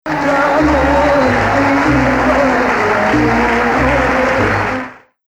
Maqam Rast
Rast 1